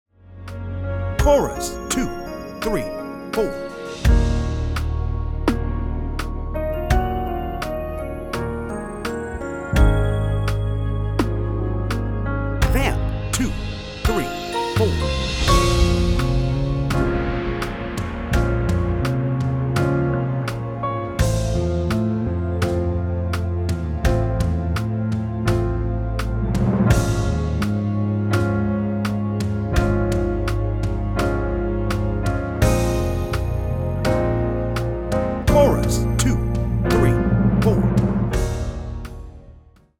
Key: Db | Tempo: 84 BPM. https